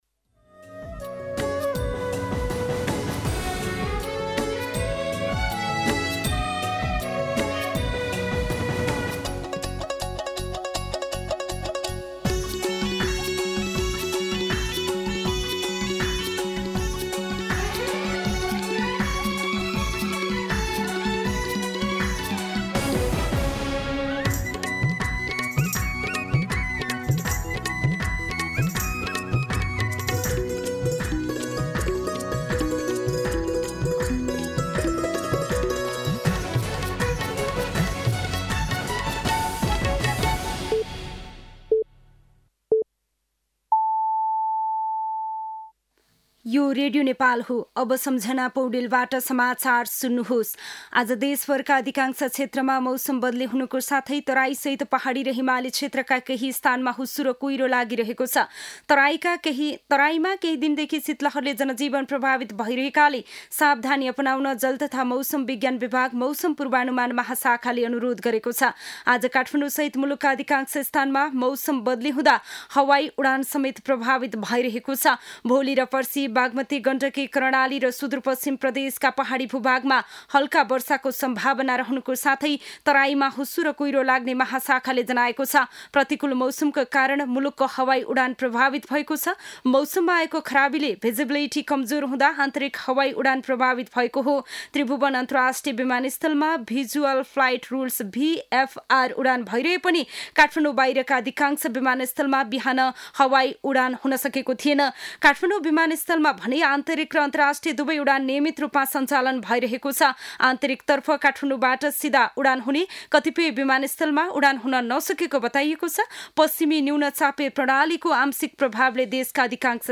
दिउँसो ४ बजेको नेपाली समाचार : २२ पुष , २०८१
4-pm-nepali-news-1.mp3